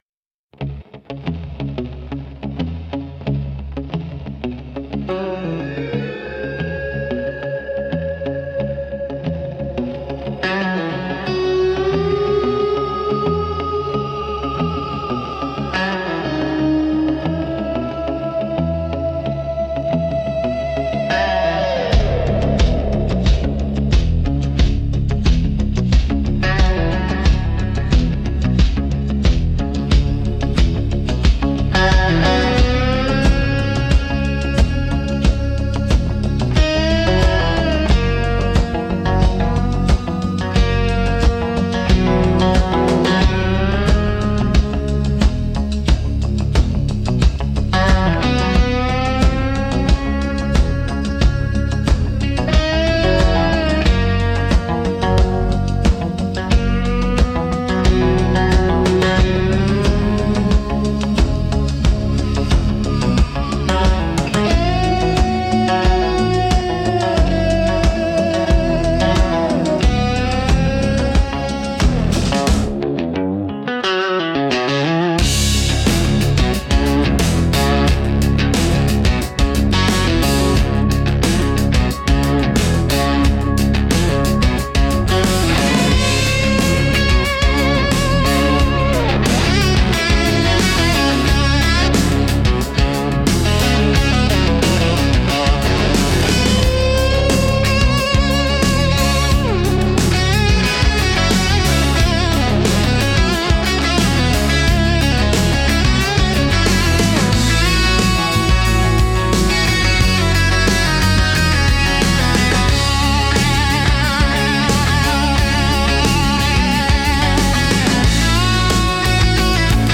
Instrumental - Tarnished Sunbeam 4.24